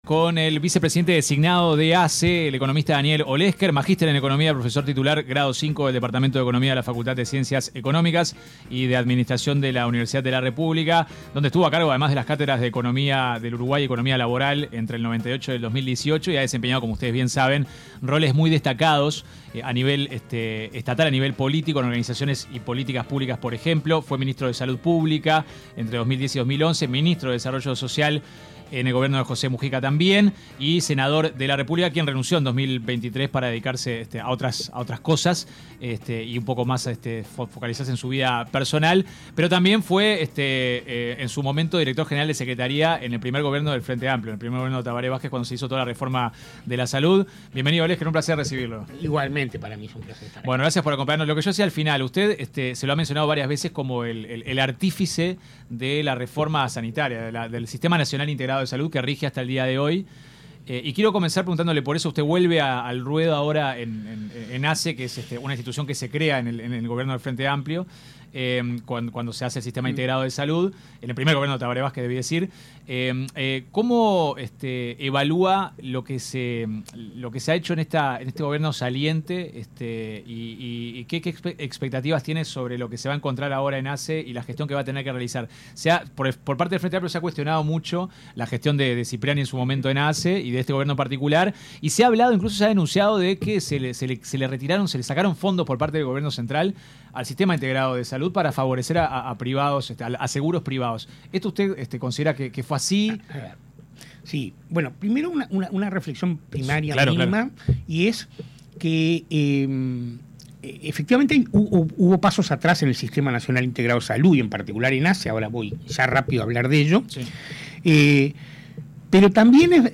El vicepresidente de ASSE designado, Daniel Olesker dijo en entrevista con 970 Noticias que la situación de Casa de Galicia y del Casmu “son dos casos distintos.